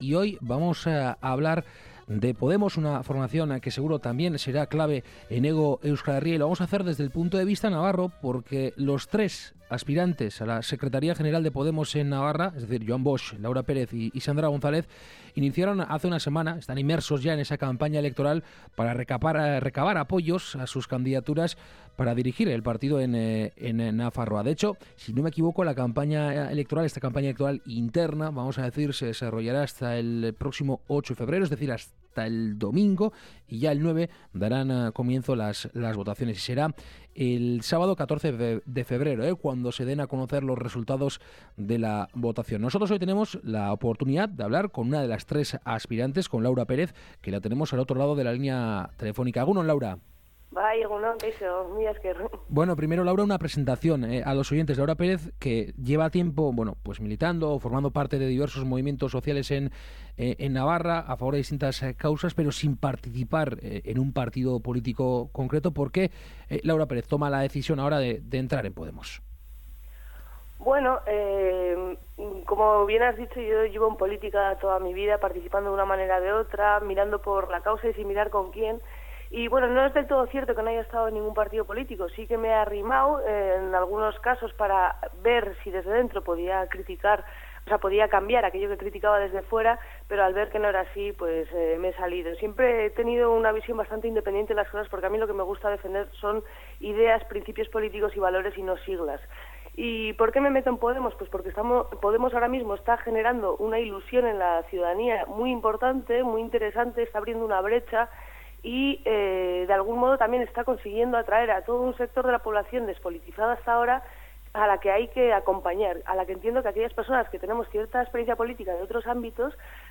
Nosotros, en Kalegorrian, hemos entrevistado